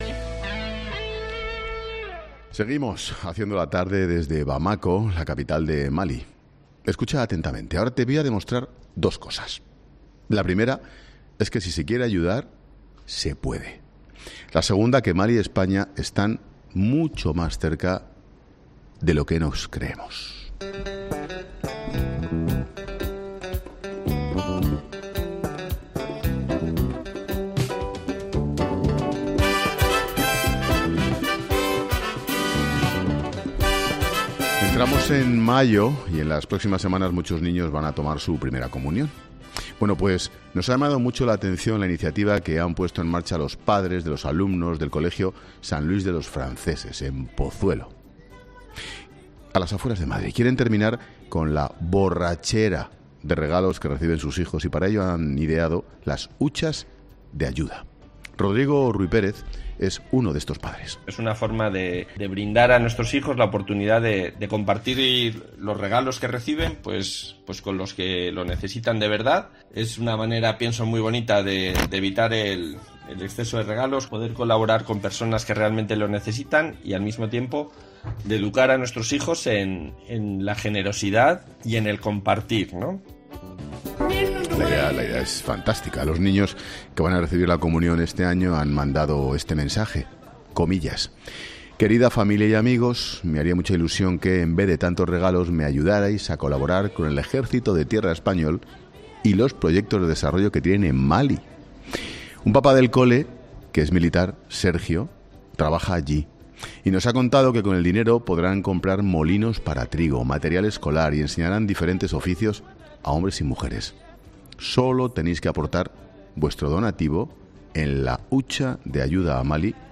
Ángel Expósito entrevista en Mali a uno de los profesores españoles que enseña castellano en los centros de enseñanza